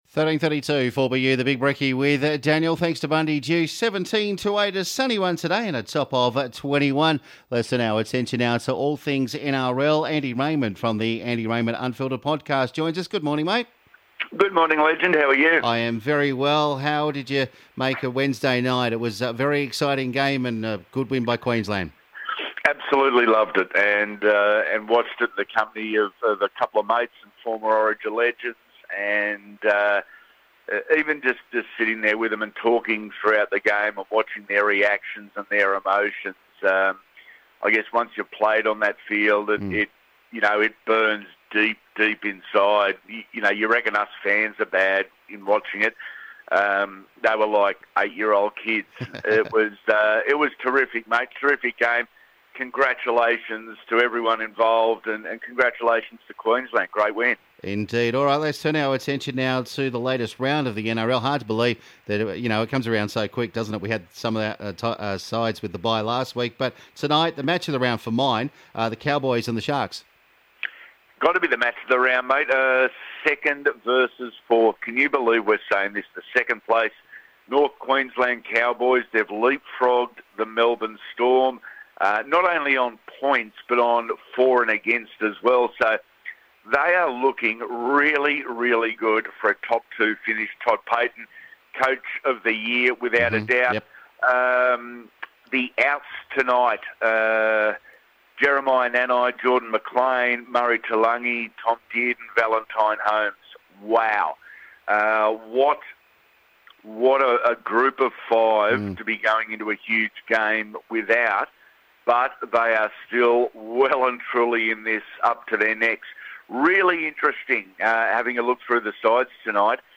a chat about this weekends games